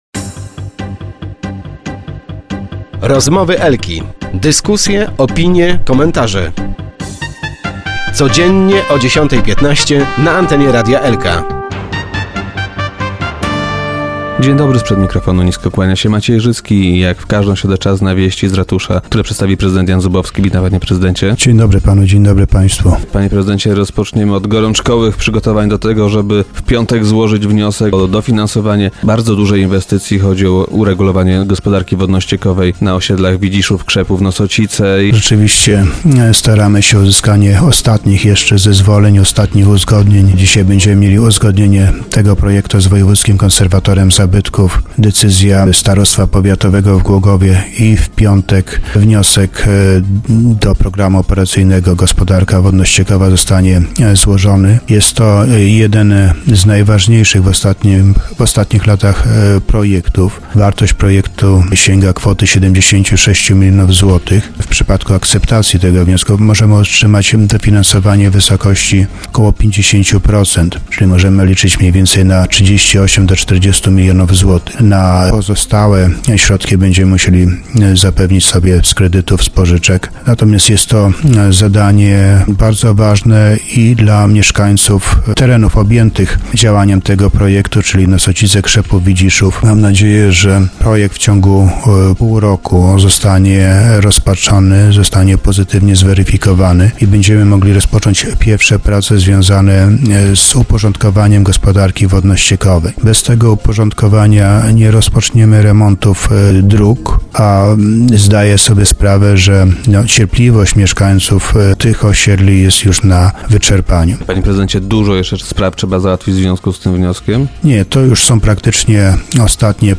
Wystosowaliśmy pismo do urzędu marszałkowskiego, z wnioskiem, by zarząd województwa jeszcze raz przeanalizował tą decyzję, by zarządy trzech województw, przez które przebiega ta trasa, doszły do porozumienia - powiedział nam prezydent Zubowski.